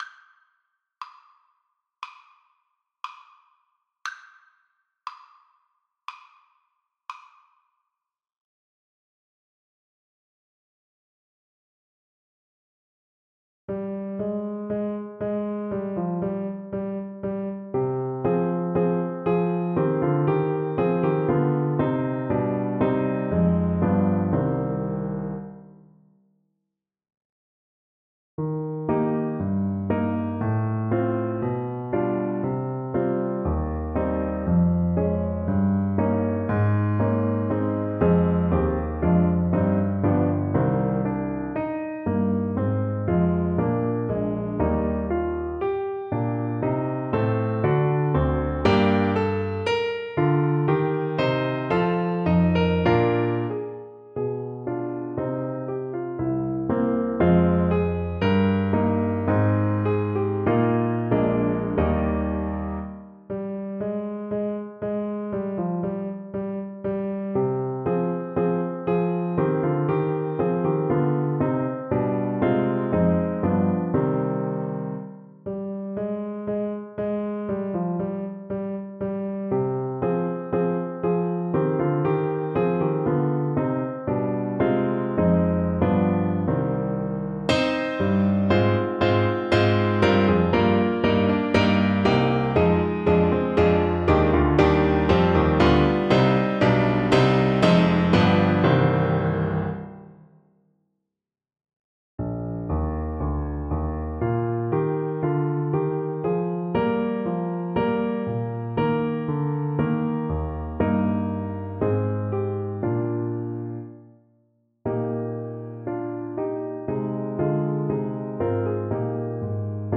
4/4 (View more 4/4 Music)
Classical (View more Classical Voice Music)